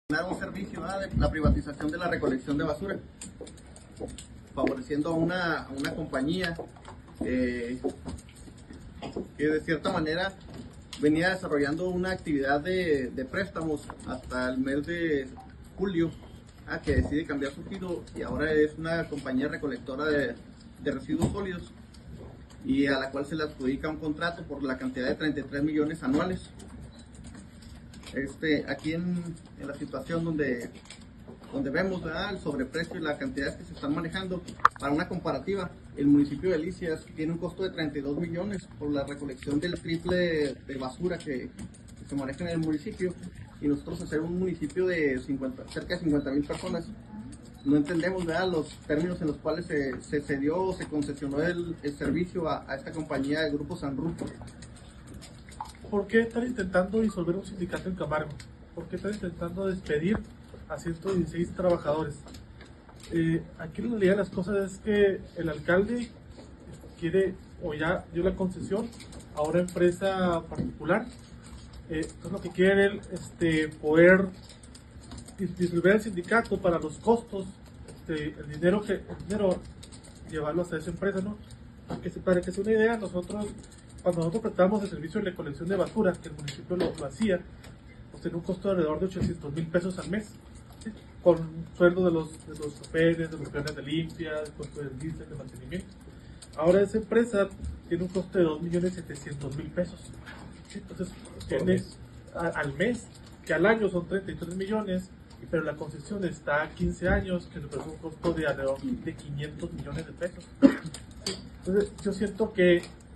En la rueda de prensa de los miércoles del Grupo Parlamentario de Morena, trajeron a dos dirigentes de los trabajadores sindicalizados del gobierno municipal de Camargo que fueron despedidos por la administración, y argumentaron que son despidos injustificados y que les han retenido el sueldo.